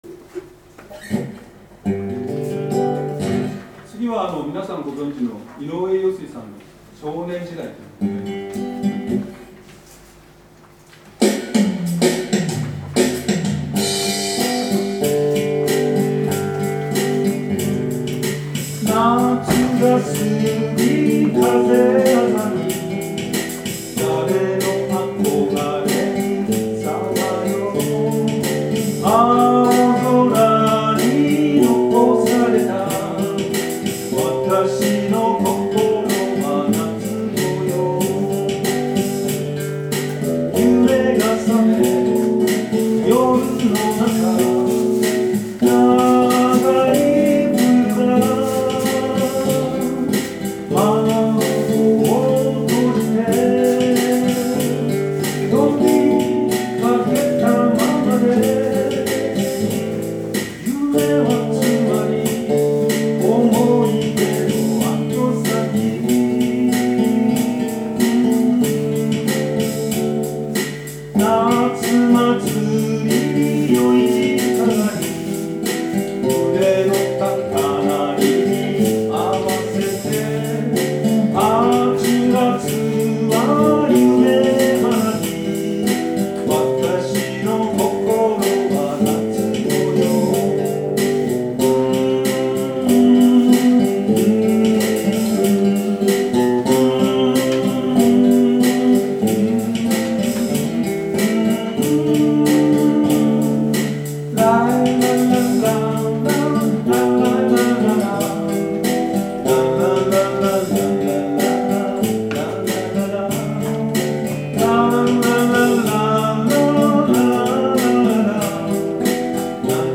偕行社文化祭２０１９